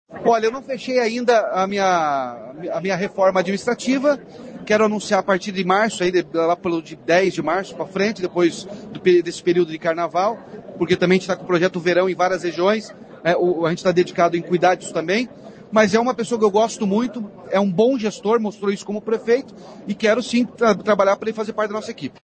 Em coletiva de imprensa o governador Ratinho Junior disse que irá fazer a reforma administrativa após o carnaval e quer que o ex-prefeito Ulisses Maia componha a equipe.